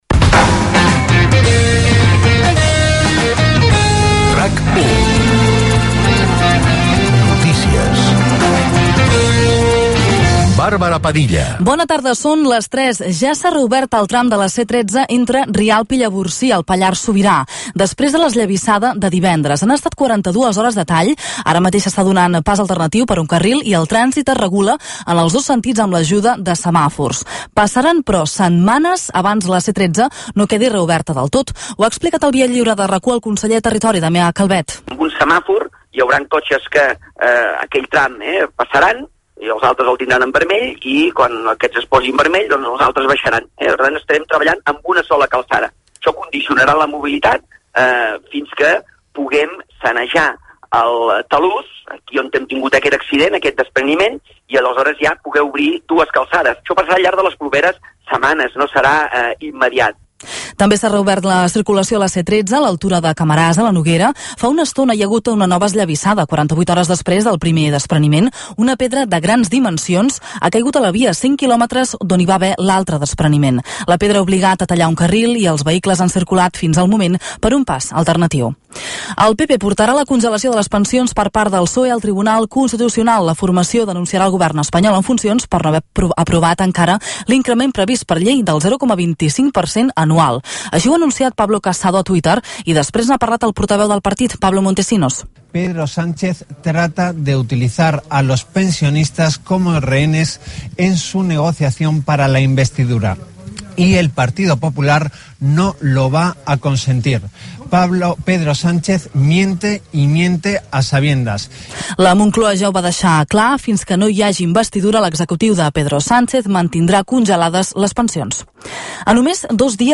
Notícies: butlletí informatiu de les 15h - RAC1, 2019